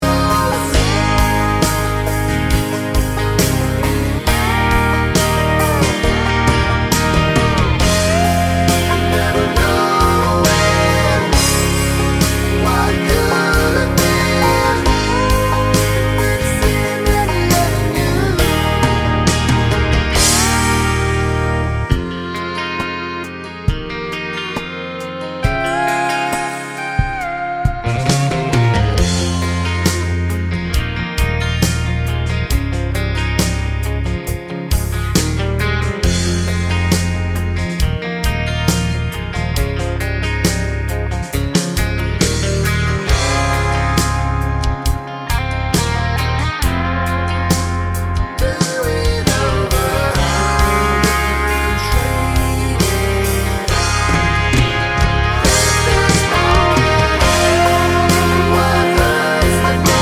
no Backing Vocals Country (Male) 3:30 Buy £1.50